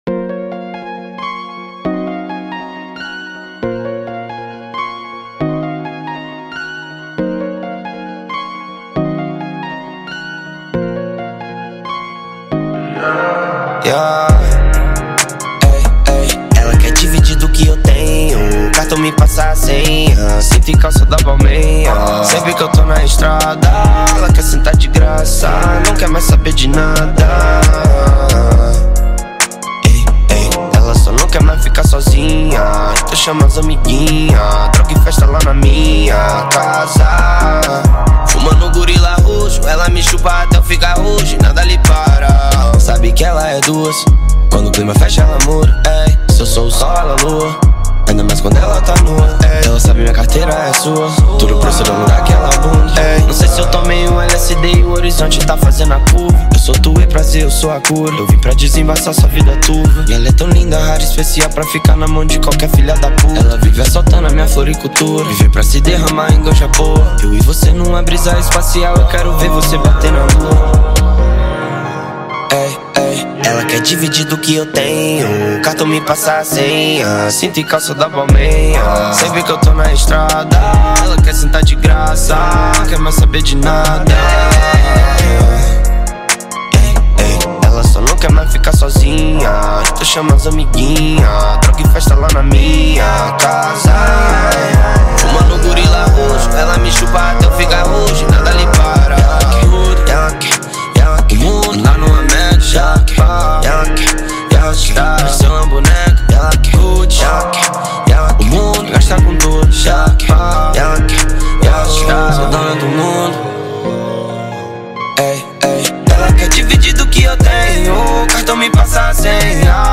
2024-04-08 19:05:23 Gênero: Trap Views